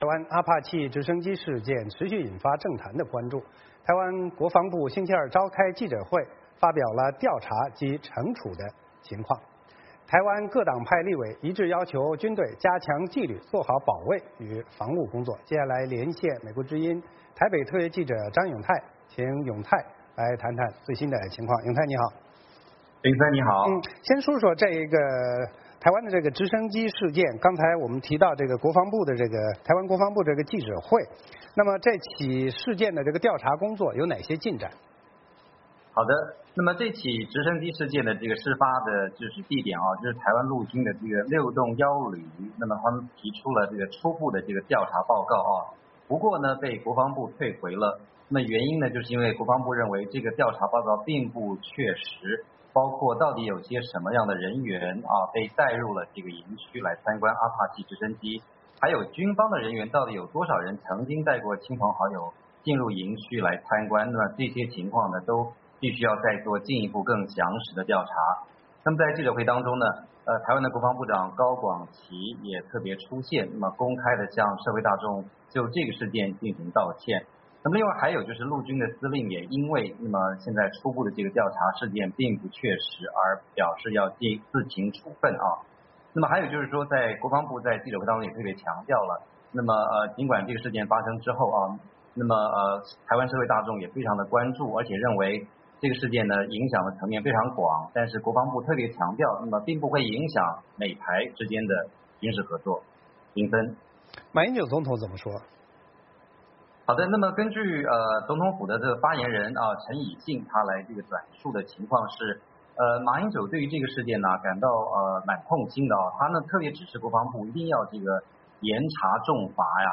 VOA连线：台湾“阿帕契”事件，立委要求军队加强纪律